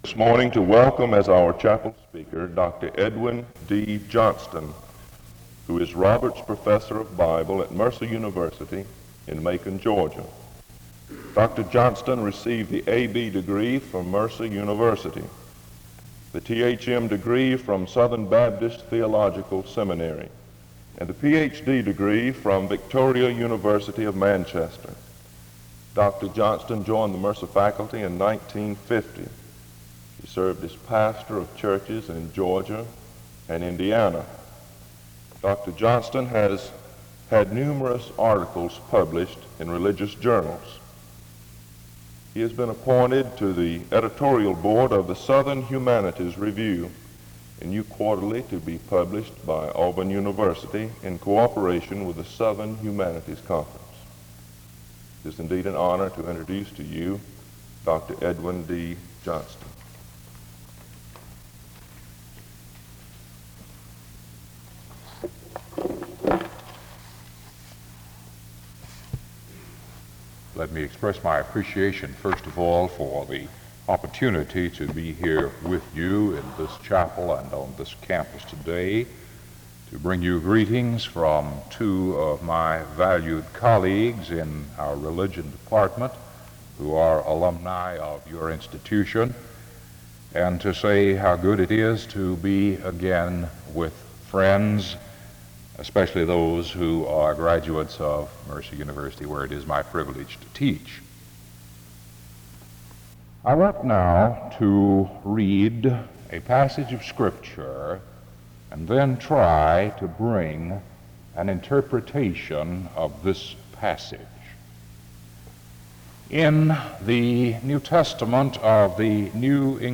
The service begins with an introduction to the speaker from 0:00-0:59.